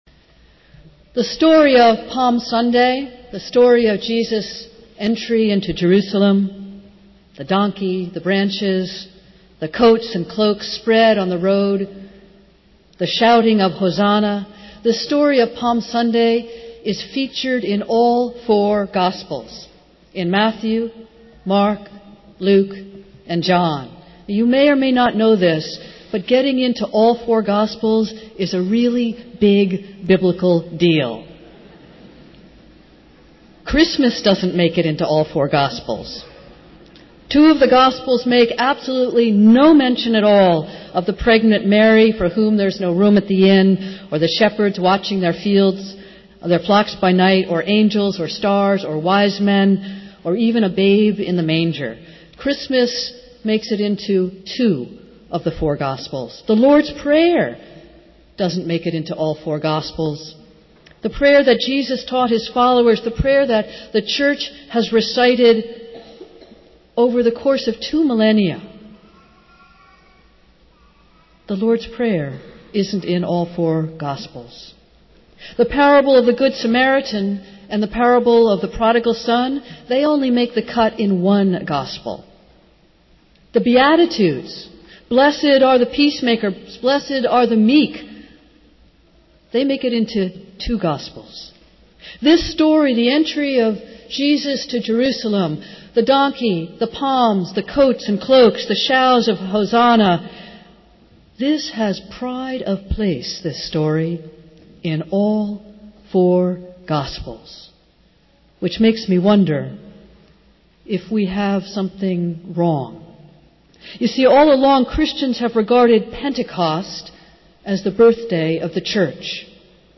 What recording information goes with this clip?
Festival Worship - Palm and Marathon Sunday